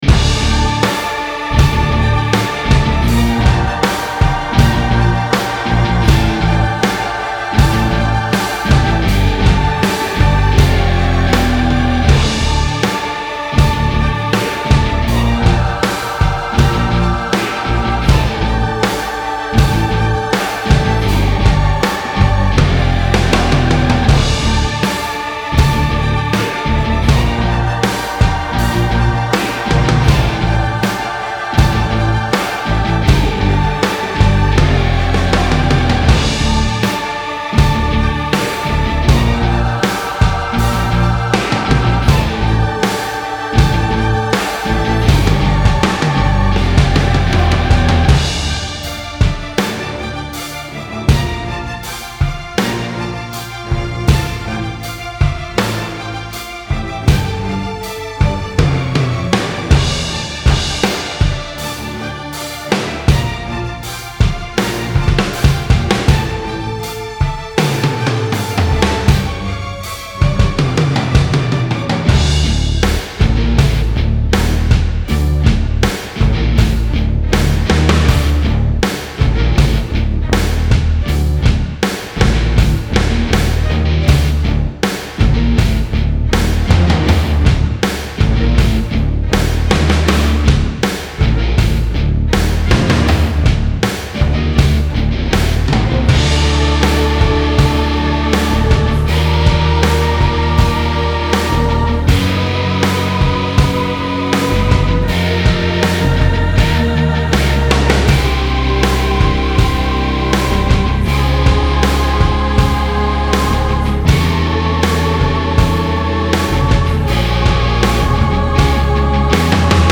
Style Style Metal, Orchestral, Rock +1 more
Mood Mood Dark, Driving, Epic +1 more
Featured Featured Bass, Choir, Drums +2 more
BPM BPM 80